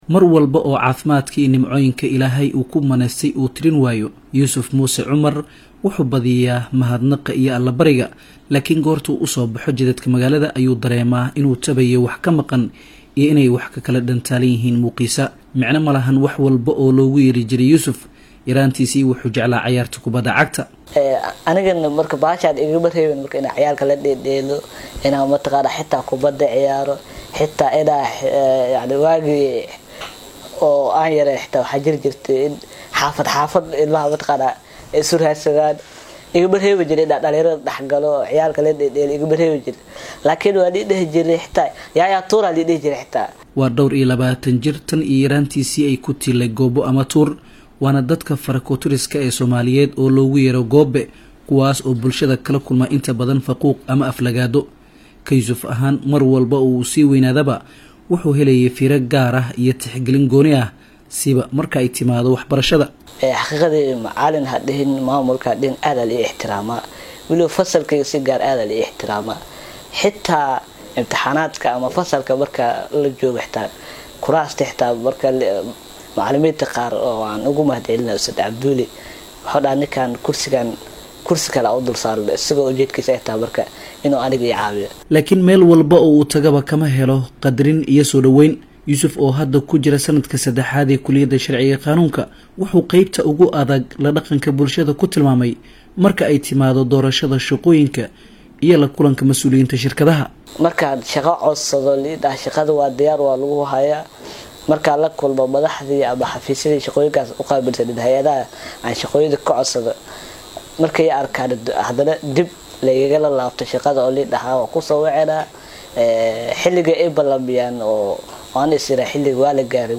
Dhibaatooyinka ay goobaha shaqada kala kulmaan dadka lixaadkoo dhimanyahay, warbixin